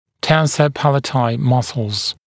[‘tensə ‘pælətaɪ ‘mʌslz][‘тэнсэ ‘пэлэтай ‘маслз]мышцы, сжимающие нёбо